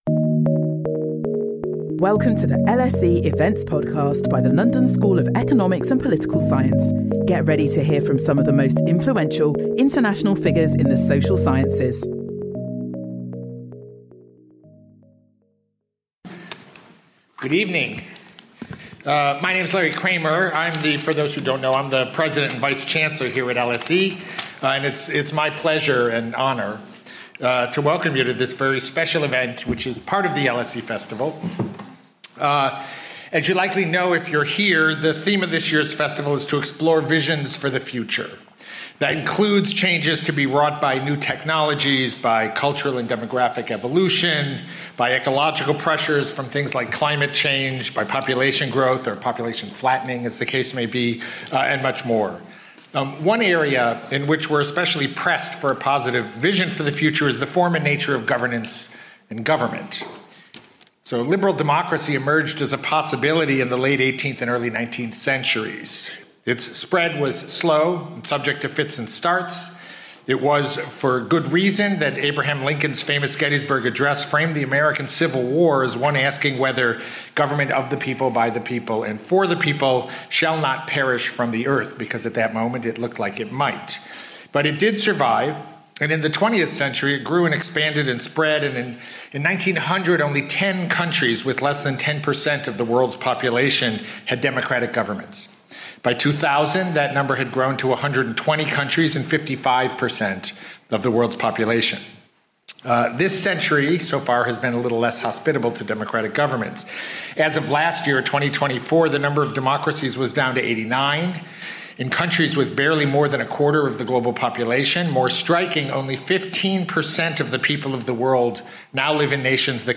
Daron Acemoglu, LSE alumnus and co-recipient of the 2024 Nobel Prize in economics, whose work has provided new insights into why there are such vast differences in prosperity between nations, will be in conversation with LSE's President Larry Kramer, on his visions for the future and will speak about Remaking Liberalism.